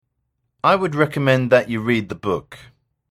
（ネイティブ音声付）